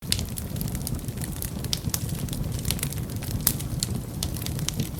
fire.ogg